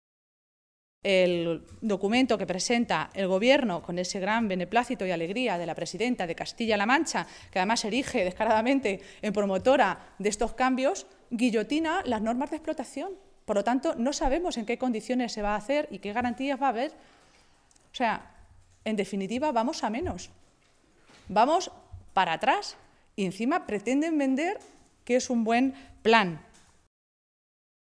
Maestre hacía estas declaraciones en una comparecencia ante los medios de comunicación, en Toledo, en la que valoraba la propuesta de Plan Hidrológico del Tajo que ha publicado hoy en el Boletín Oficial del Estado el Ministerio de Agricultura.
Cortes de audio de la rueda de prensa